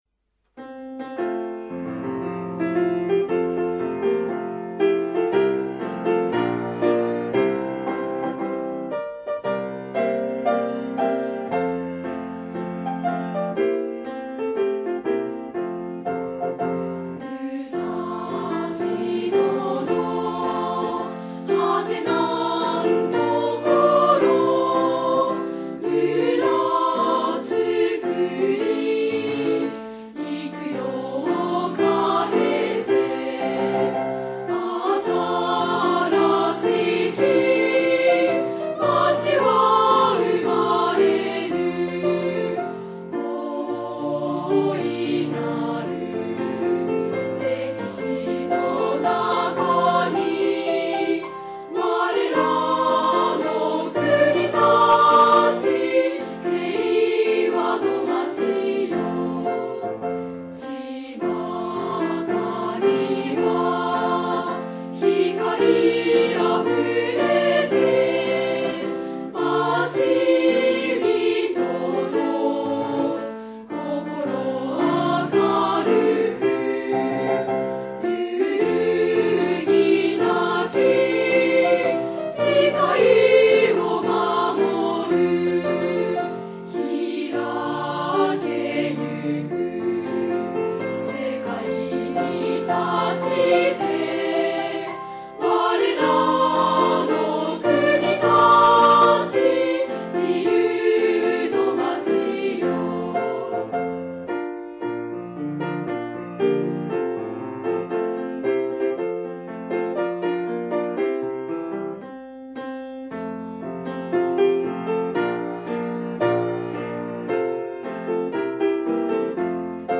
合唱
新たな収録は平成25年3月22日くにたち市民芸術小ホールのスタジオで行いました。